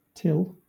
wymowa:
enPR: tĭl, IPA/tɪl/